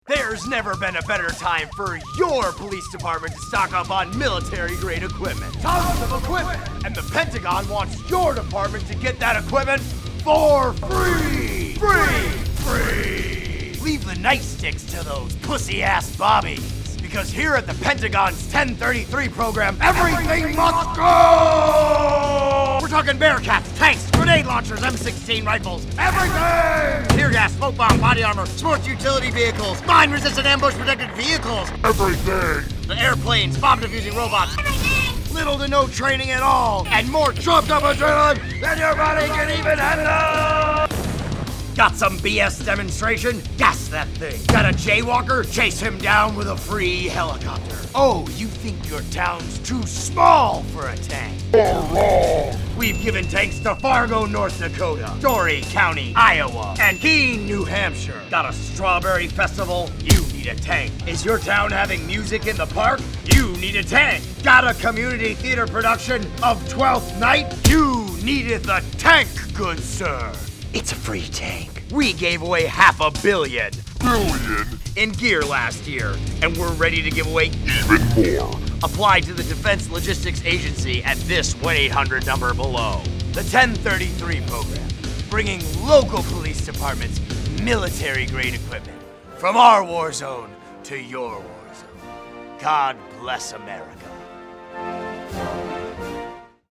After protestors in Ferguson, Missouri, were met with a militarized police force, new attention was brought to the Pentagon's 1033 program, a program that supplies military-grade equipment to local police departments, often for free. Check out a commercial Reason TV has unearthed advertising the program to law enforcement.